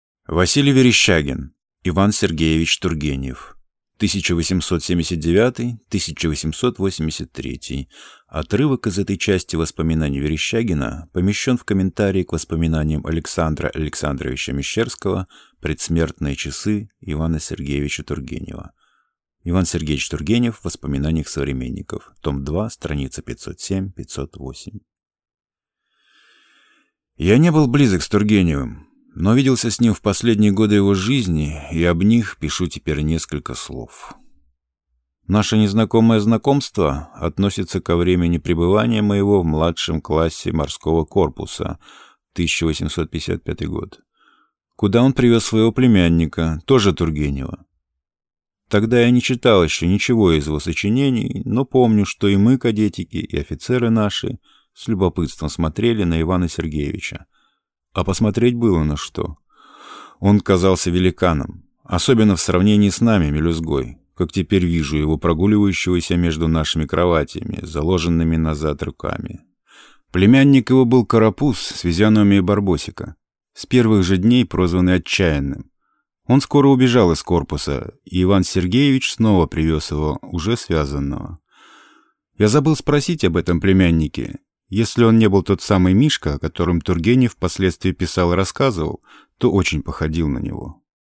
Аудиокнига И. С. Тургенев | Библиотека аудиокниг
Прослушать и бесплатно скачать фрагмент аудиокниги